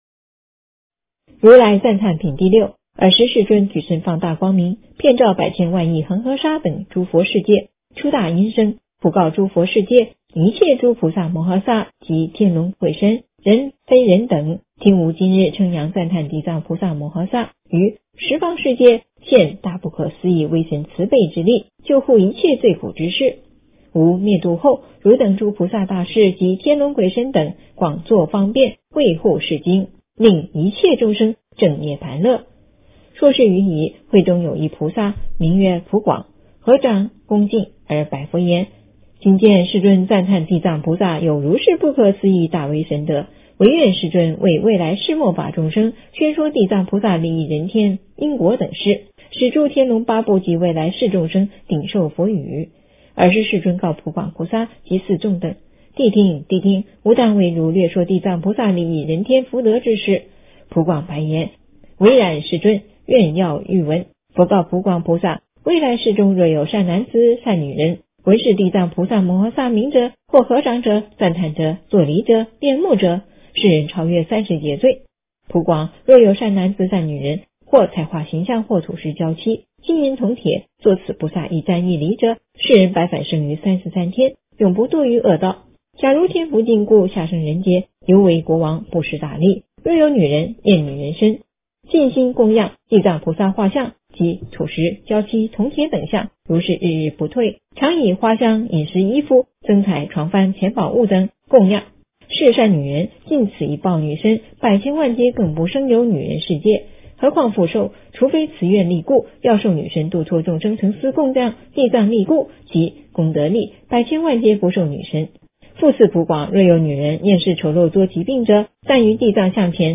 诵经
佛音 诵经 佛教音乐 返回列表 上一篇： 地藏经-地狱名号品第五 下一篇： 地藏经-称佛名号品第九 相关文章 般若波罗密多心经 般若波罗密多心经--董事长乐团...